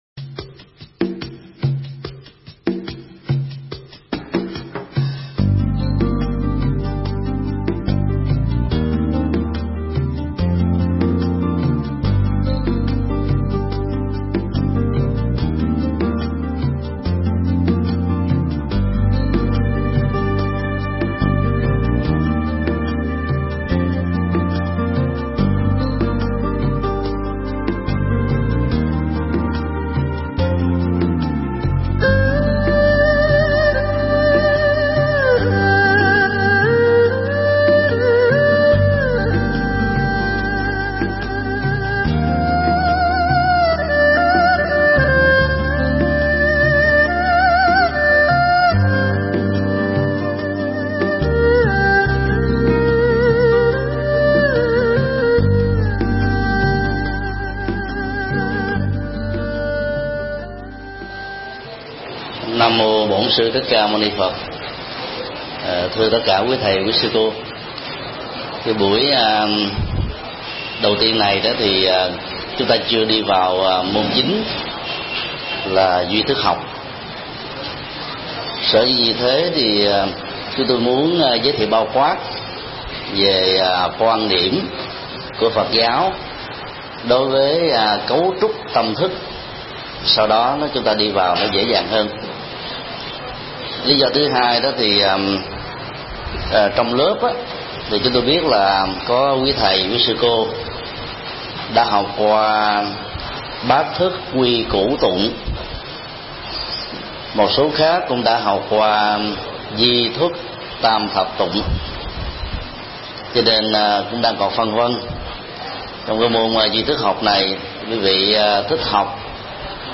Mp3 Pháp thoại Thành duy thức luận 1: Cấu trúc tâm thức